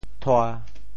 “拖”字用潮州话怎么说？